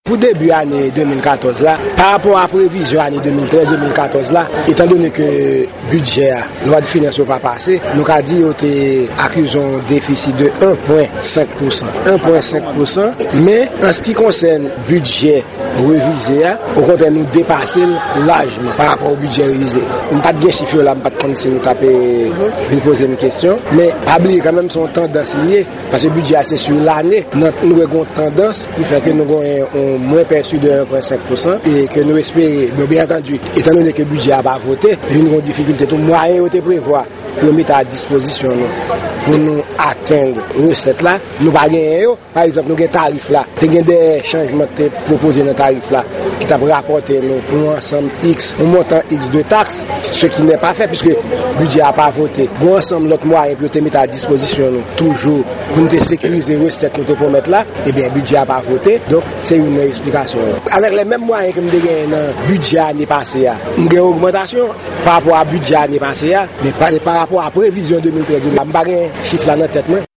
Deklarasyon direktè dwann yo ann Ayiti, Fresnel Jean-Baptiste